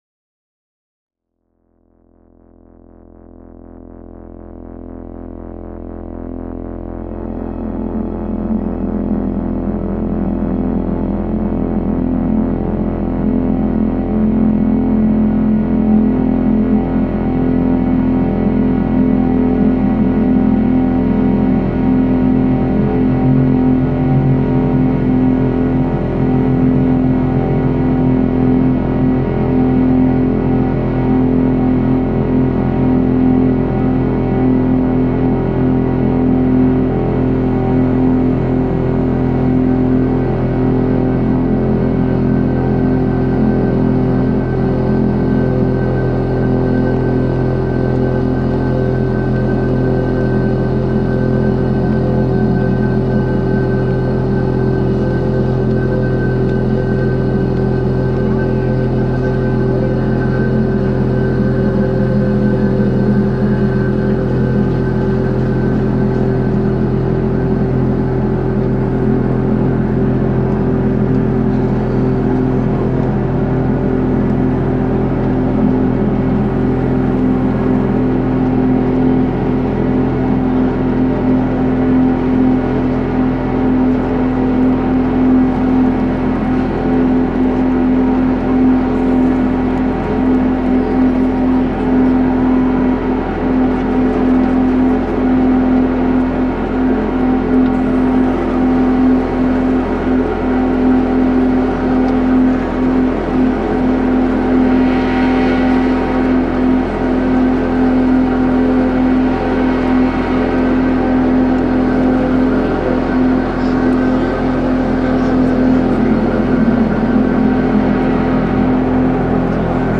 Millwall football crowd reimagined